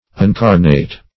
Search Result for " uncarnate" : The Collaborative International Dictionary of English v.0.48: Uncarnate \Un*car"nate\, a. Not fleshly; specifically, not made flesh; not incarnate.